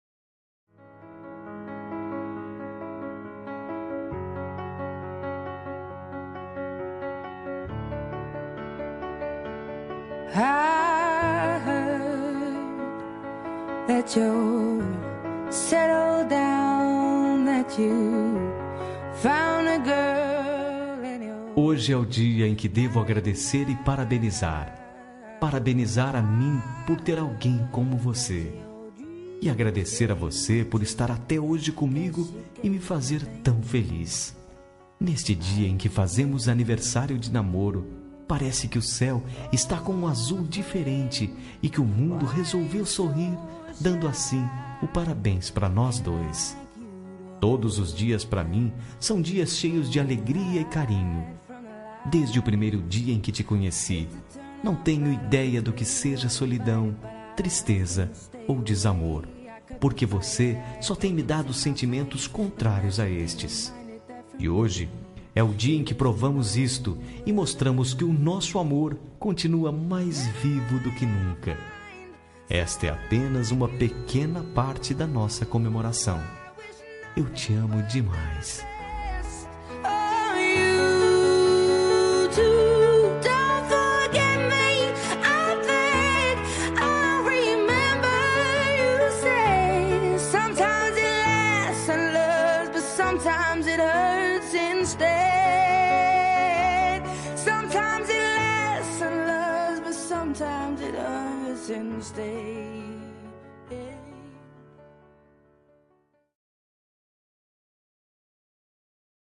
Aniversário de Namoro – Voz Masculina – Cód: 01763